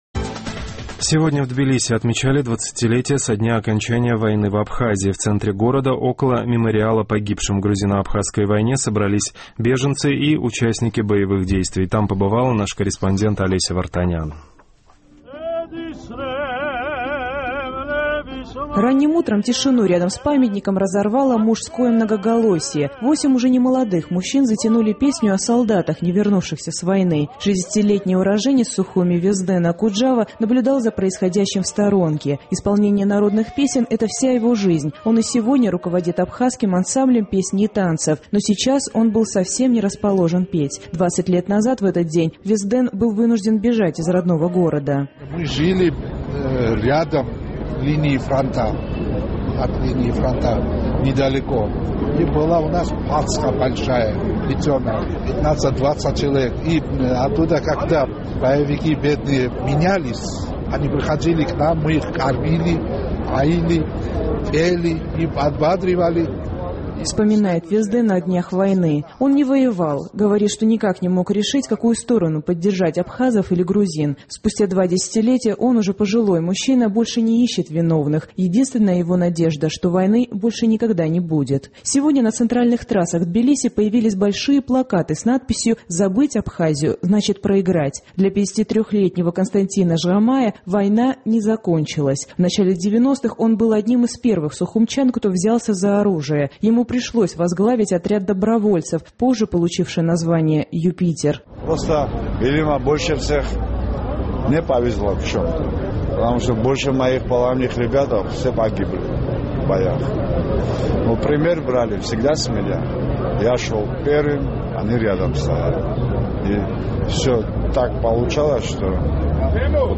В центре Тбилиси около Мемориала погибшим в грузино-абхазской войне на площади Героев собрались беженцы и участники боевых действий
Ранним утром тишину рядом с памятником разорвало мужское многоголосье. Восемь уже немолодых мужчин затянули песню о солдатах, не вернувшихся с войны.